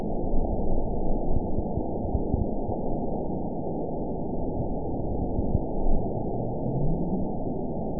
event 920355 date 03/18/24 time 03:10:05 GMT (1 year, 3 months ago) score 9.62 location TSS-AB03 detected by nrw target species NRW annotations +NRW Spectrogram: Frequency (kHz) vs. Time (s) audio not available .wav